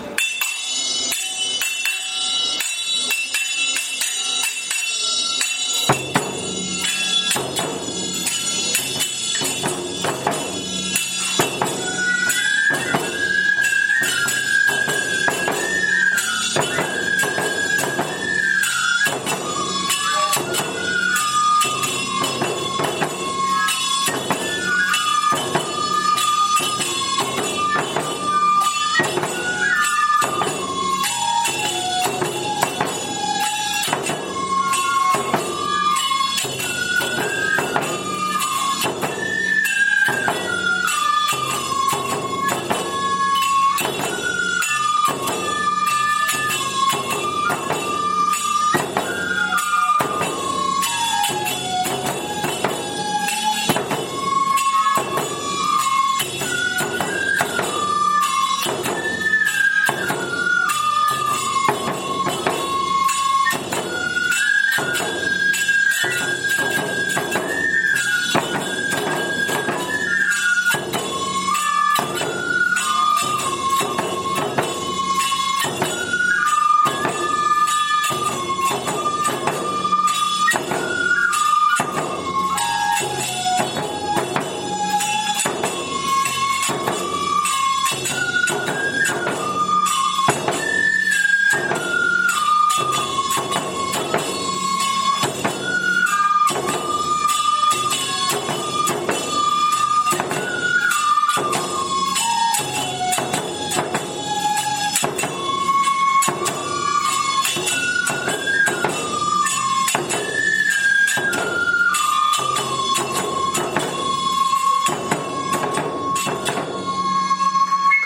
《 愛好会囃子部の演奏 》
2017(h29)年 ※電力ビル･グリーンプラザ(仙台市)
【デジタル録音】⇒ 進行ばやし
七日日ばやし